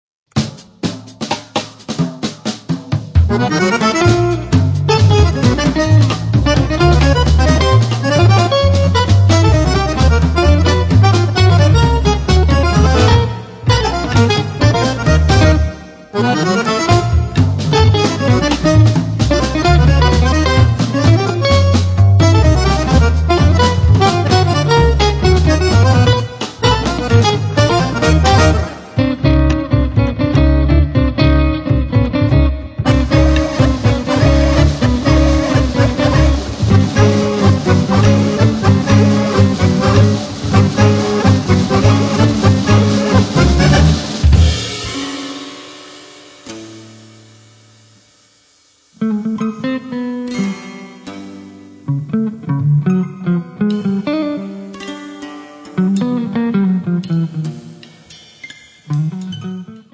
chitarra elettrica e acustica, effetti
fisarmonica, elettroniche
contrabbasso
batteria